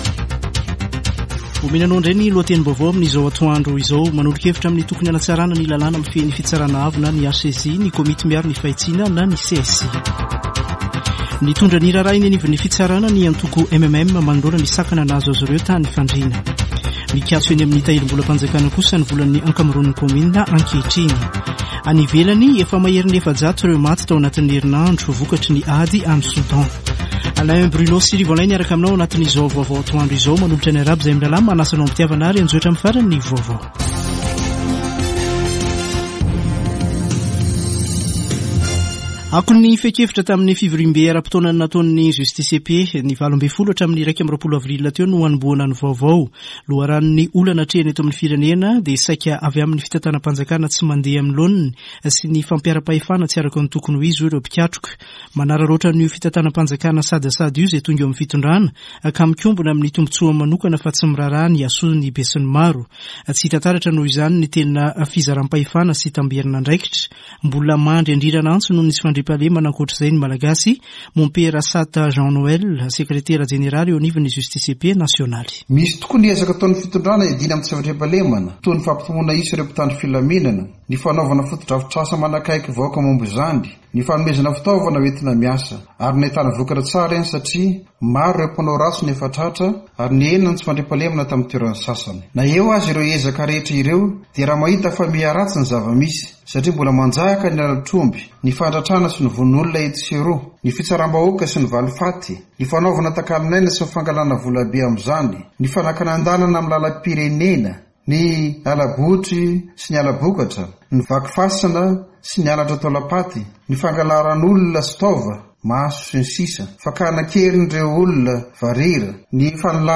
[Vaovao antoandro] Sabotsy 22 avrily 2023